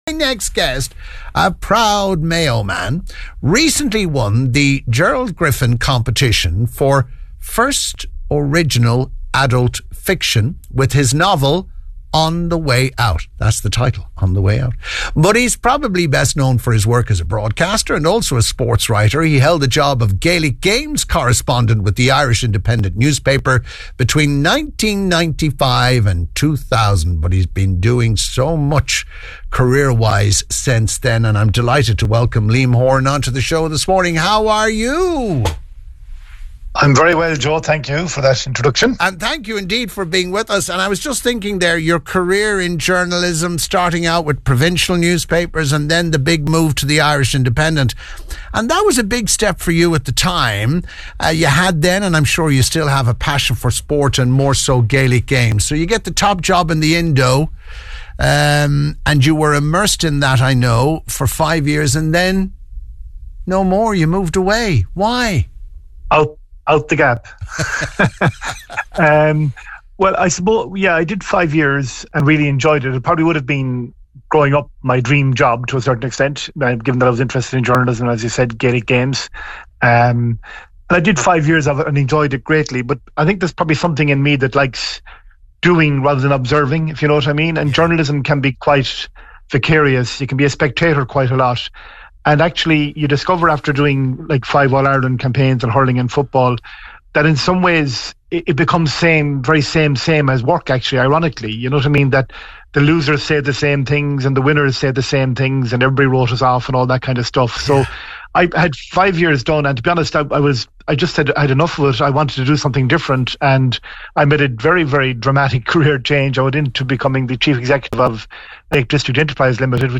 It was a great conversation with an old friend, and you can listen back here: